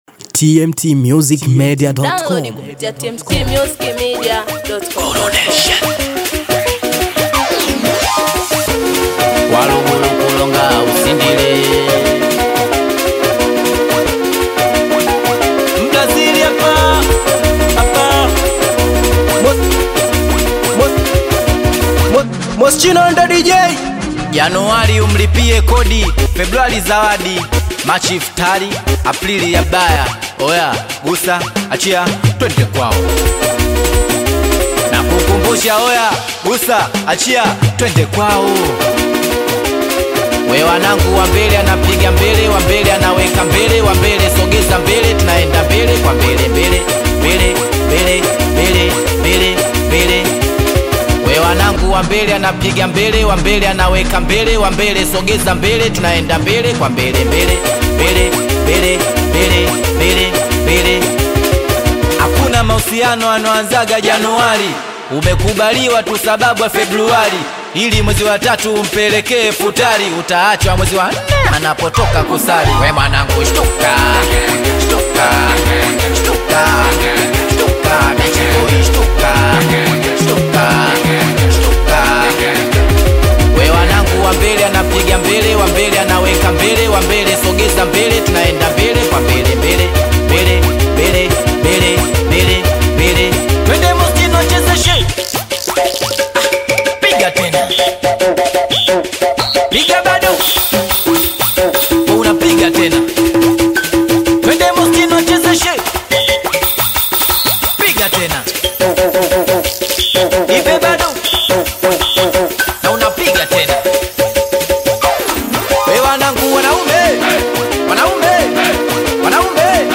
BONGO FLAVOUR